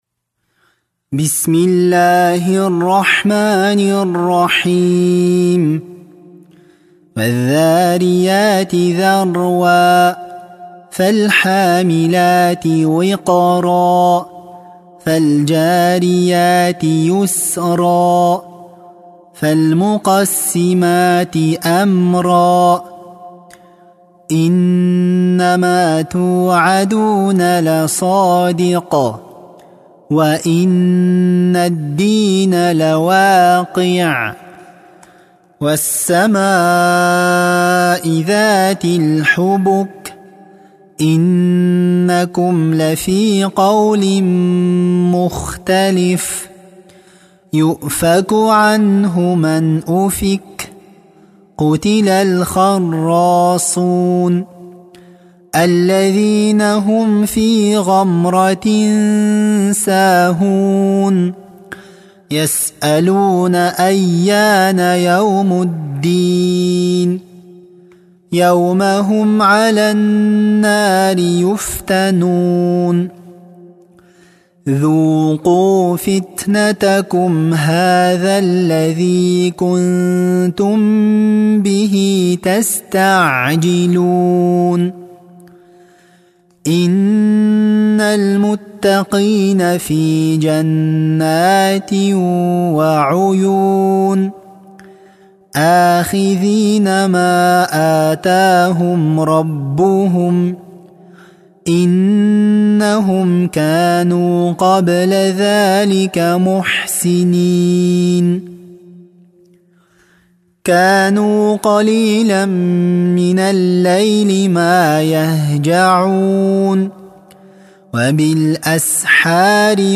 Ассаляму алейкум уа рахматуЛлахи уа баракатух! маша Аллах, ариптери оте анык естиледи екен, жаттаганга оте ынгайлы))) Осы кисинин кырагатымен дугаларды да алсак нур устине нур болар еди, мумкин болар ма екен?